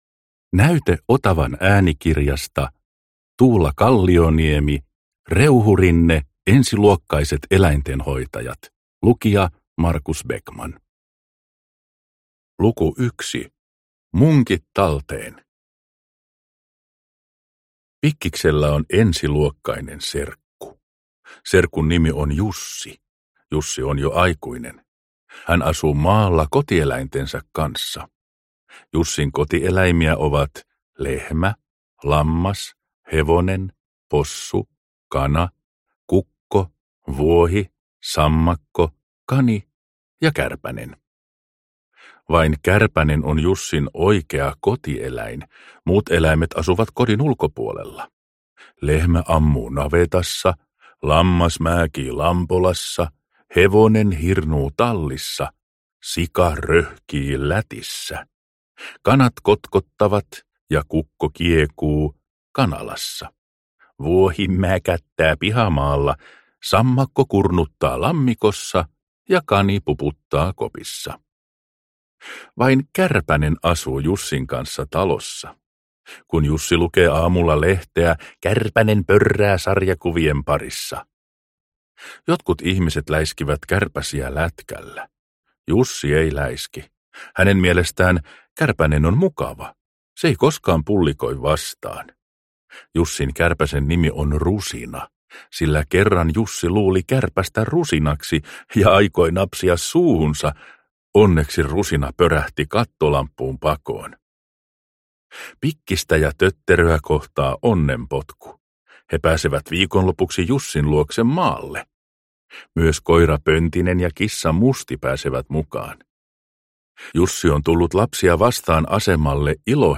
Ensiluokkaiset eläintenhoitajat – Ljudbok – Laddas ner